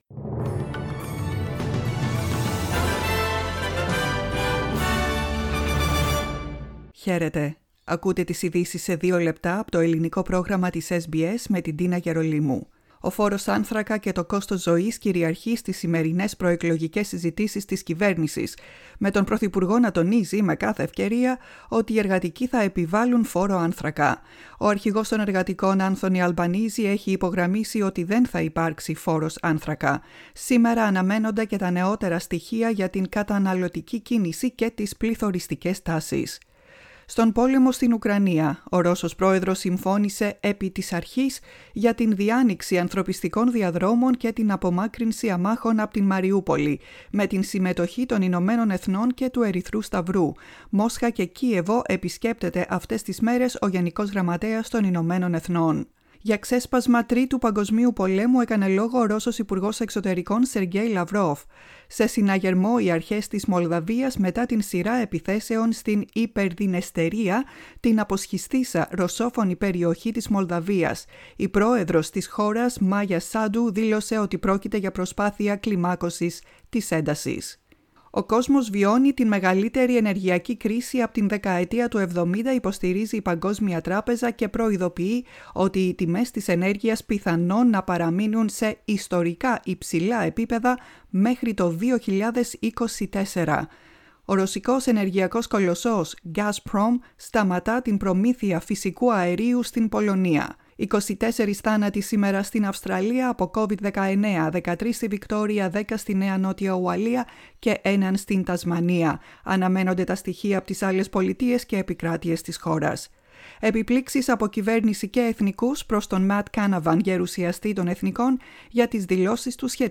Flash news: οι ειδήσεις σε δύο λεπτά 27.04.22